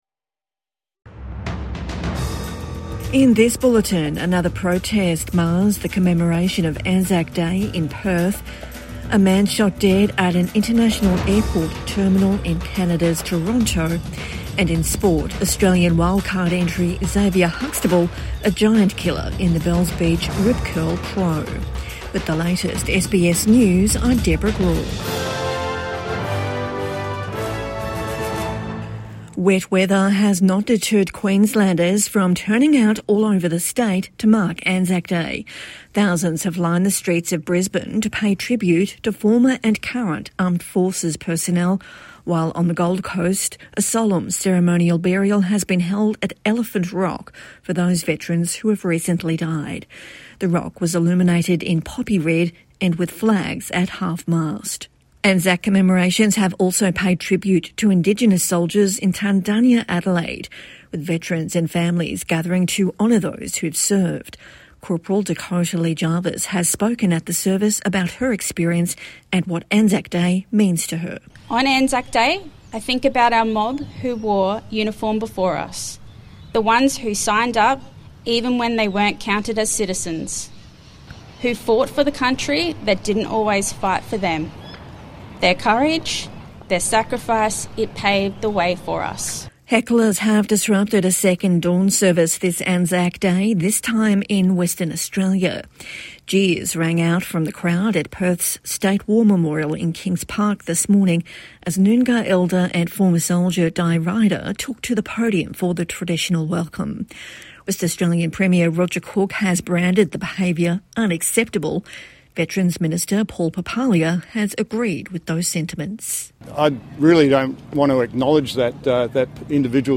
Another protest mars Anzac Day, this time in Perth | Evening News Bulletin - 25 April 2025